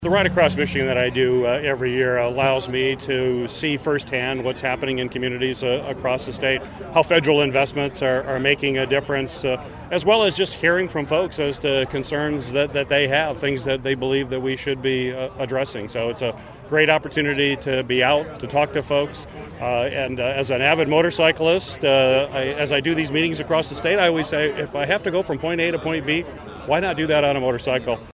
U.S. Senator Gary Peters in Kalamazoo to start the Kalamazoo to the Soo motorcycle tour
Peters launched the tour in Kalamazoo for the first time Wednesday morning.